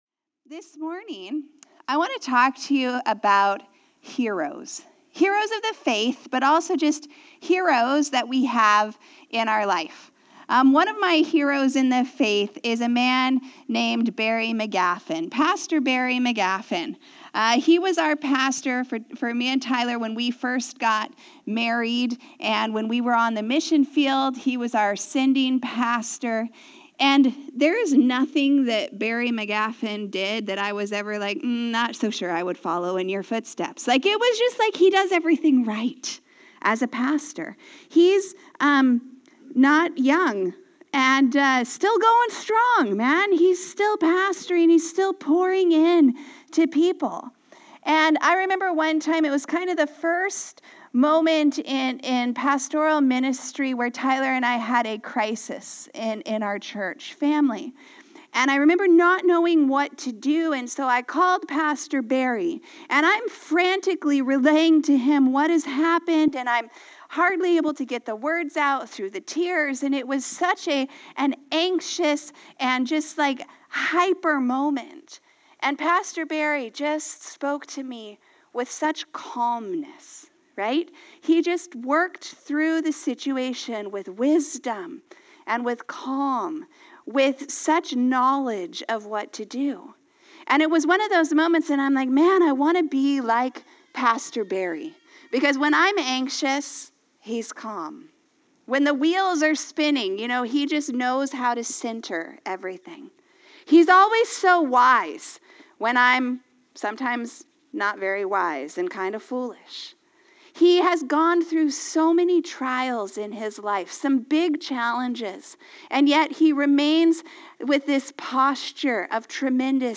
Sermons | Harvest Church